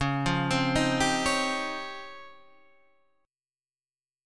DbM#11 Chord